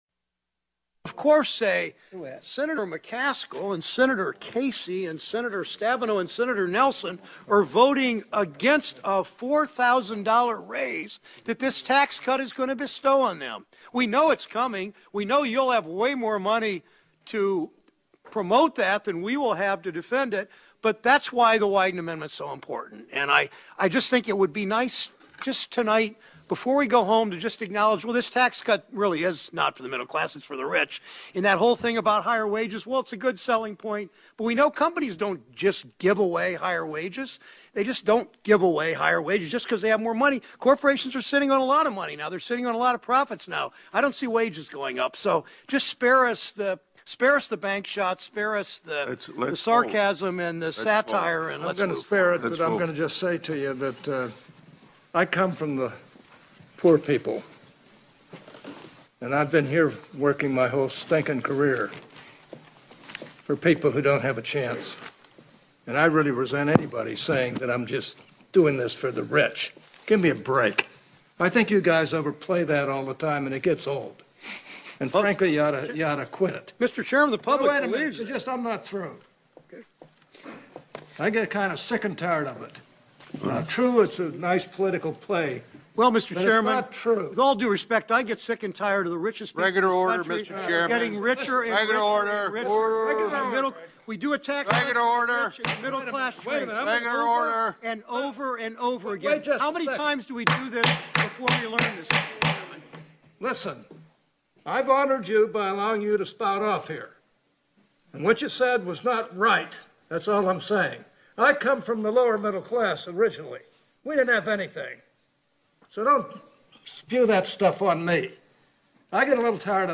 Senator Hatch gets into shouting match over tax reform
Utah Senator Orrin Hatch laid into Ohio Democrat Sherrod Brown during a Thursday night Senate Finance Committee hearing on tax reform.